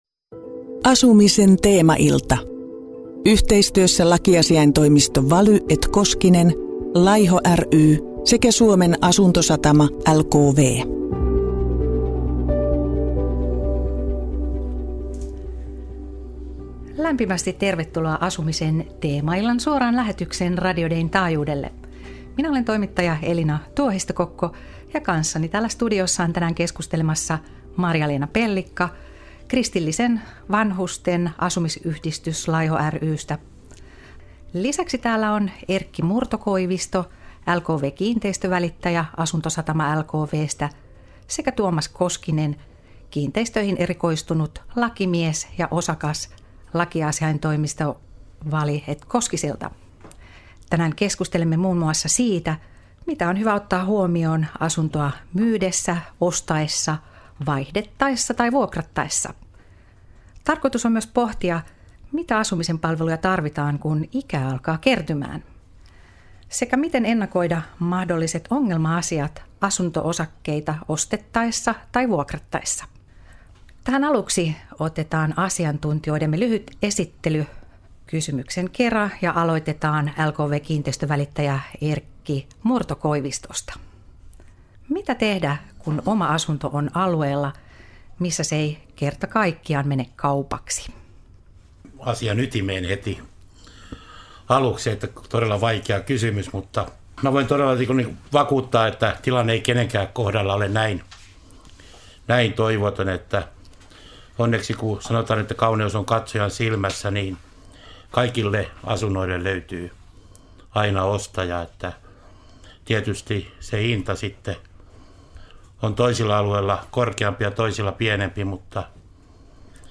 Asiantuntijat jakoivat asuntokauppa- ja vuokravinkkejä asumisen teemaillassa - Kristityt Yhdessä ry